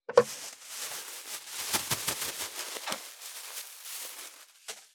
639コンビニ袋,ゴミ袋,スーパーの袋,袋,買い出しの音,ゴミ出しの音,袋を運ぶ音,
効果音